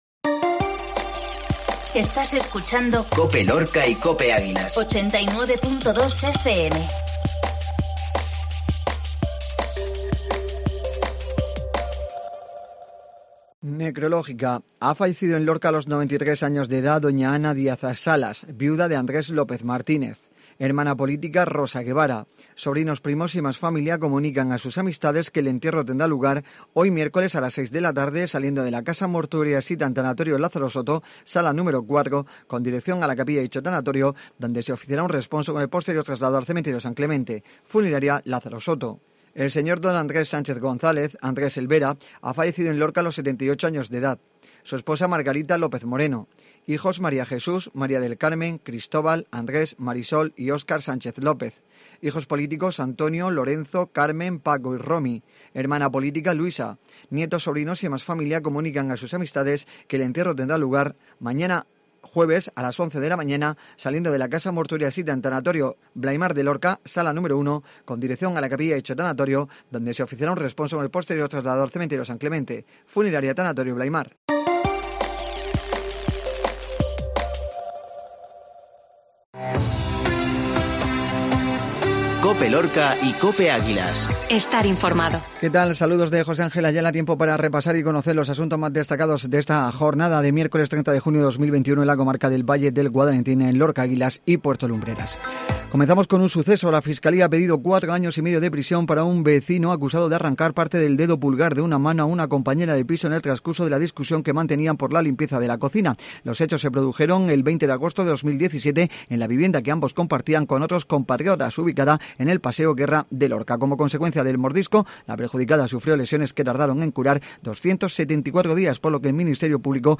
INFORMATIVO MEDIODÍA MIÉRCOLES